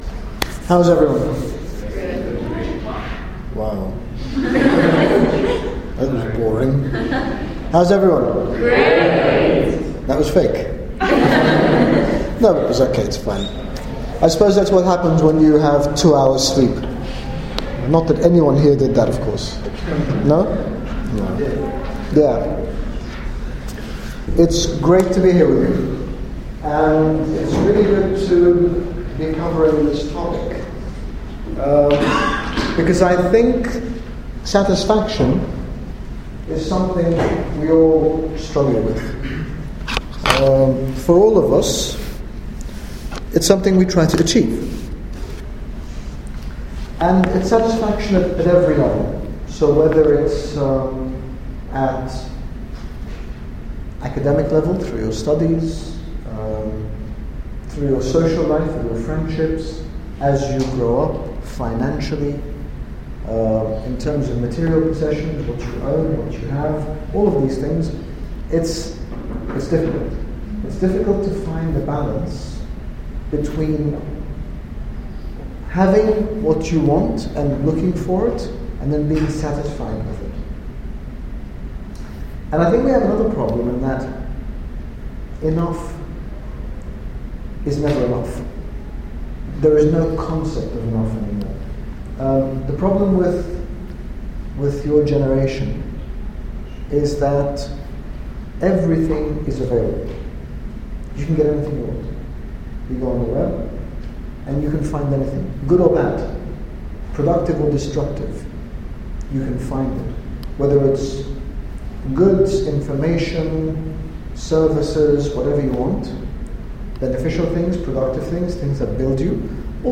In this talk His Grace Bishop Angaelos speaks about the need for satisfaction and what does and doesn't satisfy us, and the danger in valuing ourselves and others based only on the superficial.